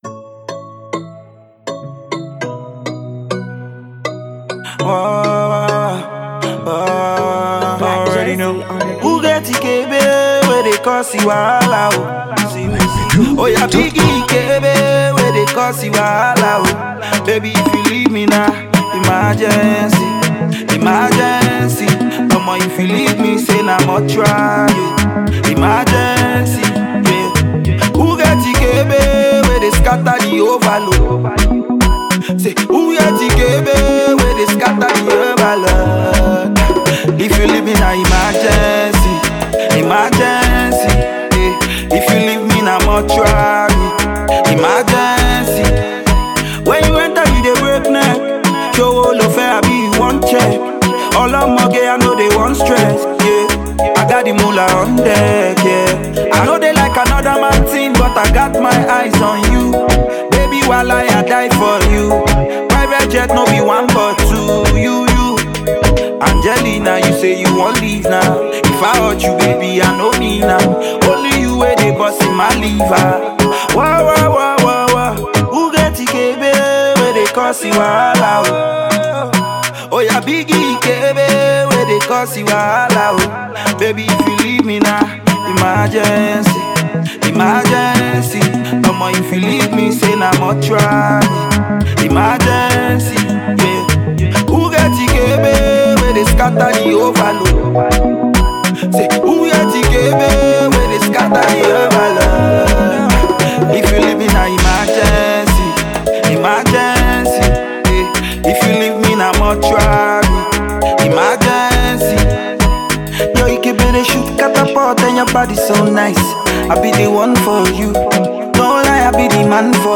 Afro pop singer
The pop single will get you off your feet.